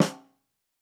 TC PERC 09.wav